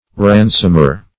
Ransomer \Ran"som*er\ (-[~e]r), n. One who ransoms or redeems.